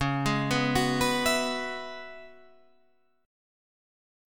C#m11 chord